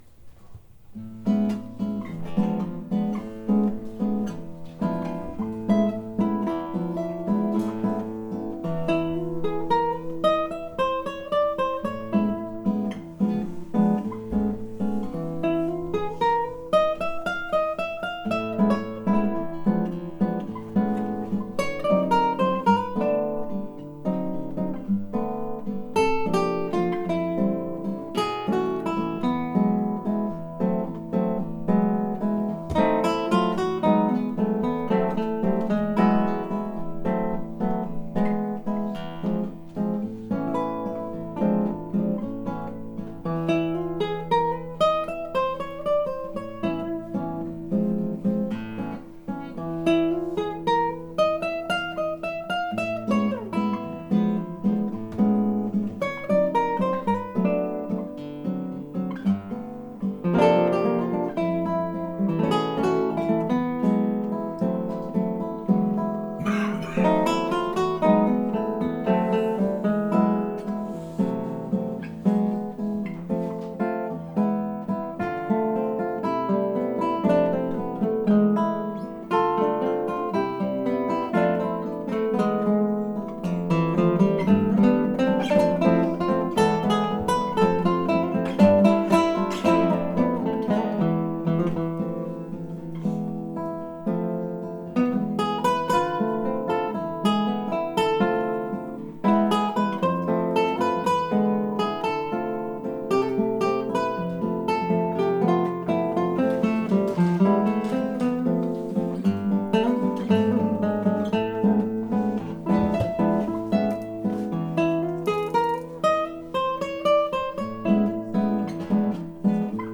at a recent shop concert
Their work draws on classical, jazz, and Latin American traditions, encompassing familiar repertoire and original compositions.